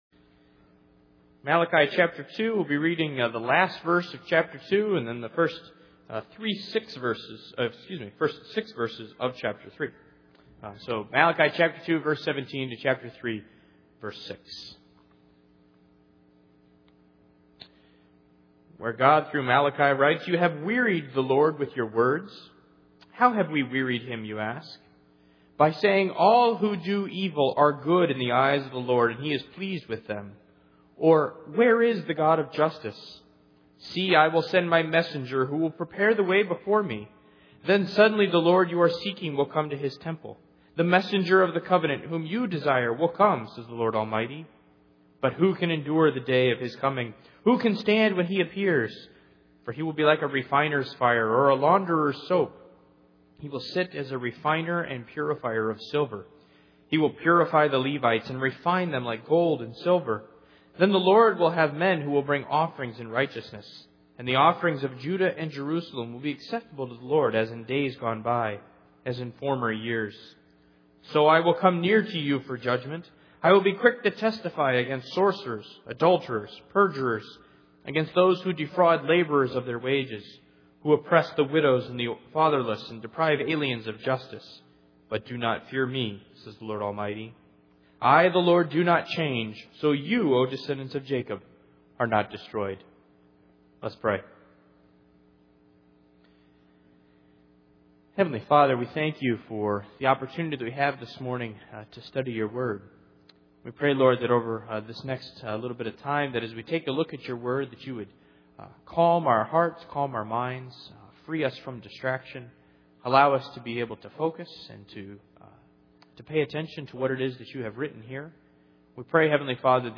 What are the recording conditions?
Malachi Passage: Malachi 2:17-3:6 Service Type: Sunday Morning %todo_render% « Those who are Loved Hate not Is Faith Worth it?